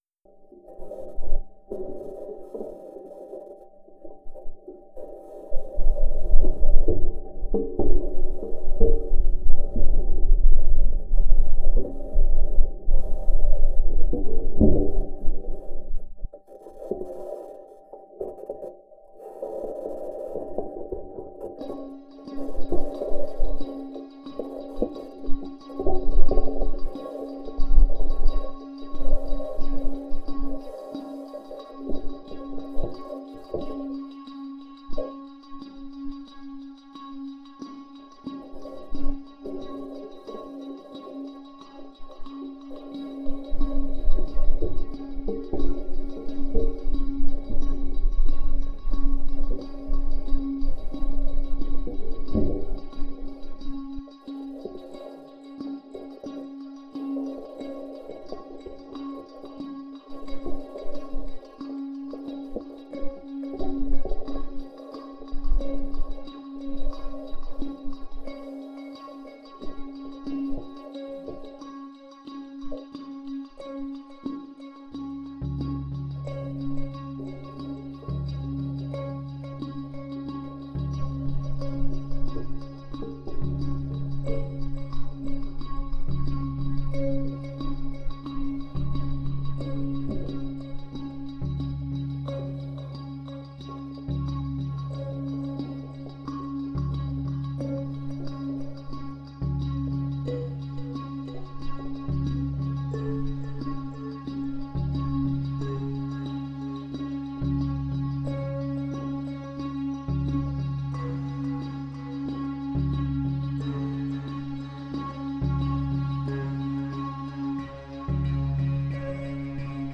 Tags: Guitar, Percussion, Digital
Title Perseverance - A Sol In The Life Opus # 530 Year 2023 Duration 00:07:20 Self-Rating 4 Description Perhaps what the Perseverance rover is experiencing on Mars. Incorporates NASA audio of the rover ambling along.